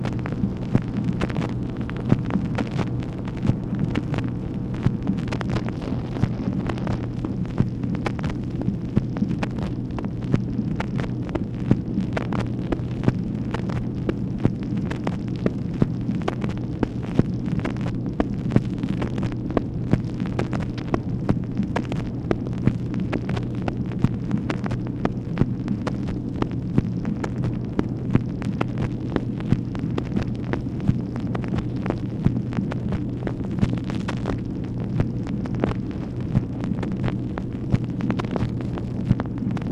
MACHINE NOISE, October 23, 1964
Secret White House Tapes | Lyndon B. Johnson Presidency